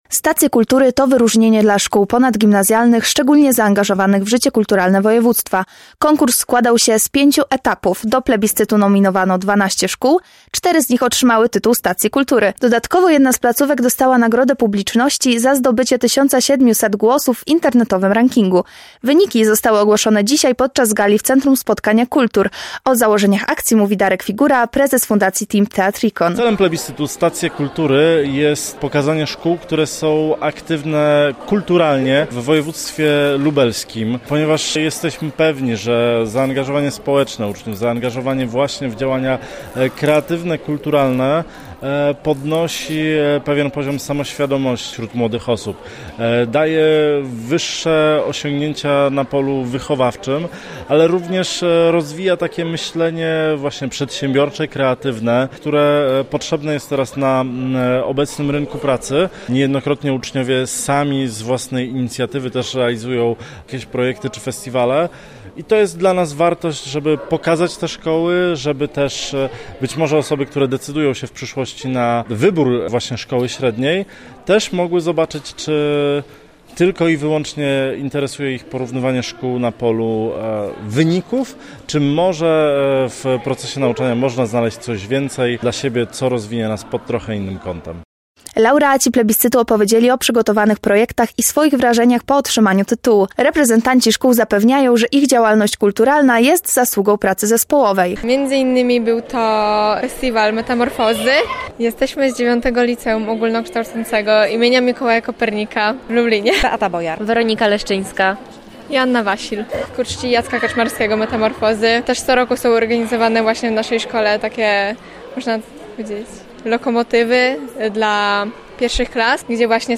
Relację z gali 6. edycji Stacji Kultury